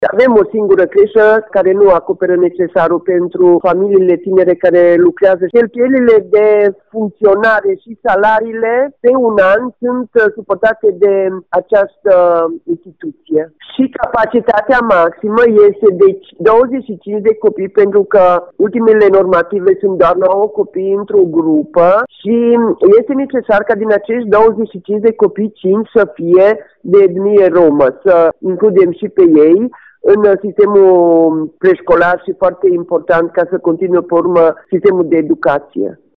Primarul municipiului Reghin, Maria Precup.